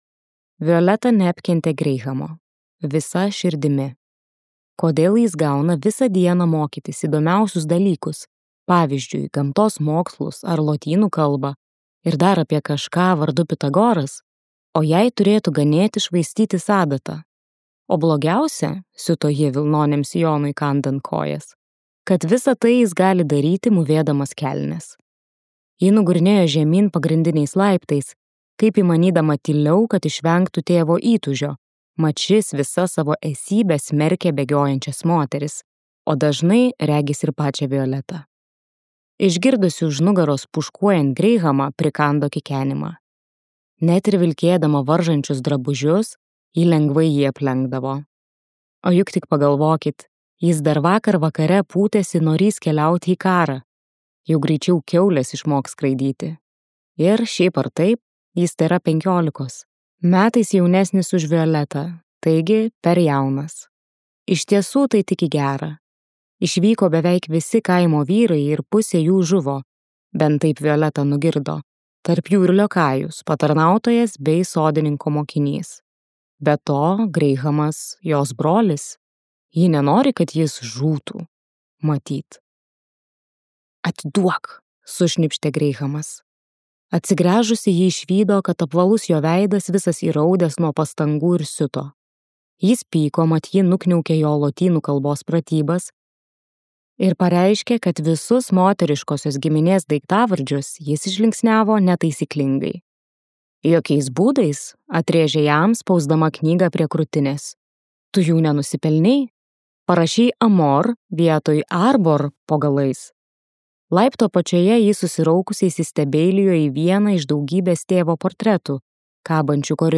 Emilia Hart audioknyga „Neklusniosios“. Tai paslaptinga ir magiška istorija apie tris kartas moterų, kurios kovoja su šeimos prakeiksmu ir atranda savo ryšį su gamta bei vidine jėga.